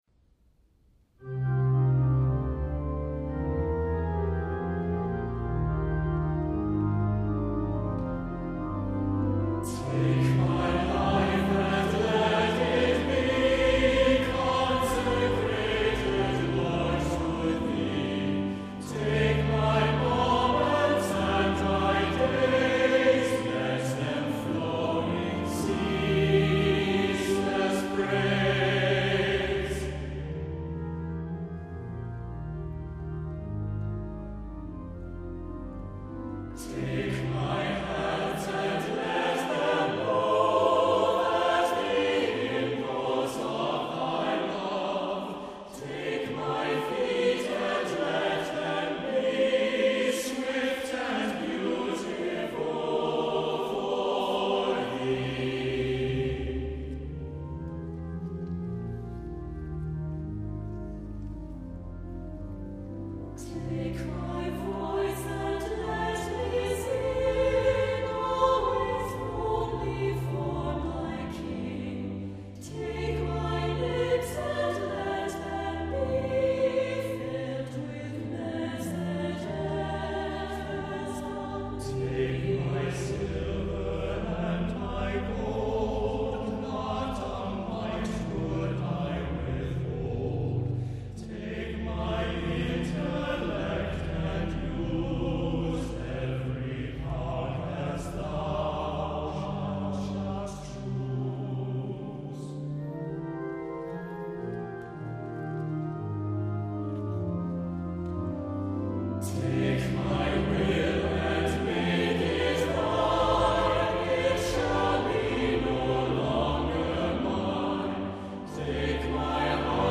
• Music Type: Choral
• Voicing: SATB
• Accompaniment: Organ
• beautiful, expressive melody for this famous text
• vocal range stays mostly in the middle